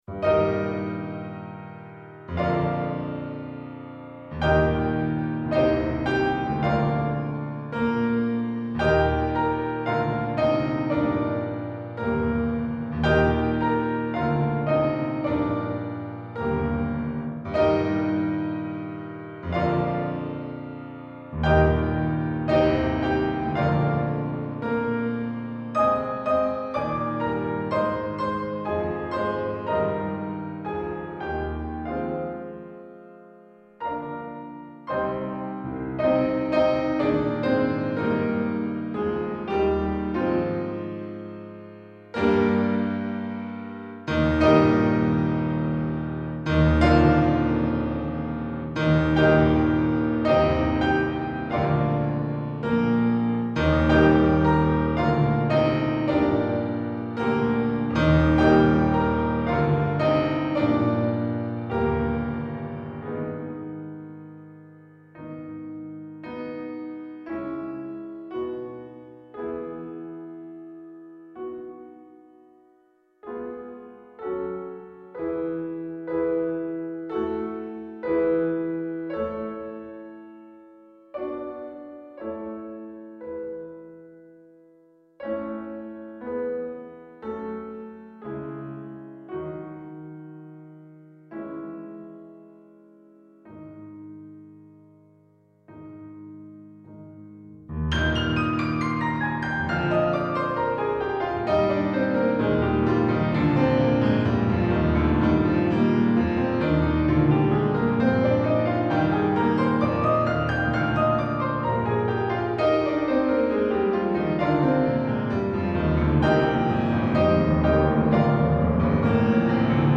Classical Music Free License MP3 Downloads
Mussorgsky-Pictures-at-an-Exhibition-La-Grande-Porte-de-Kiev-2024-Piano.mp3